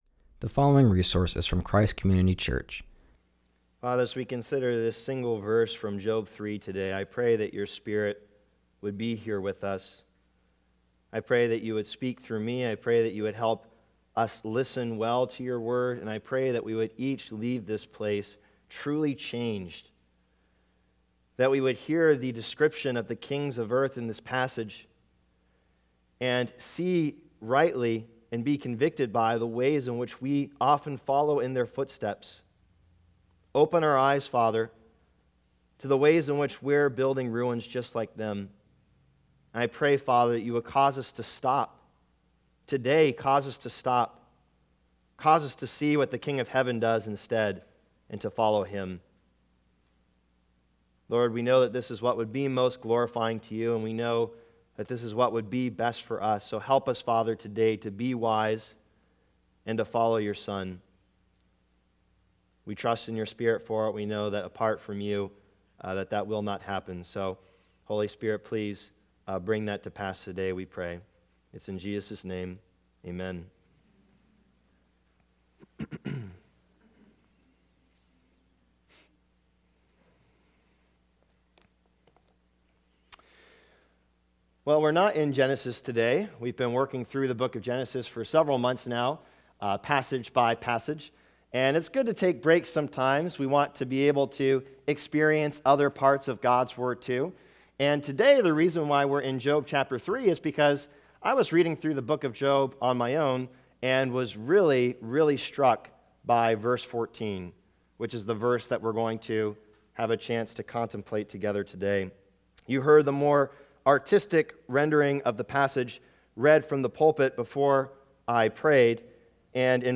preaches from Job 3:14.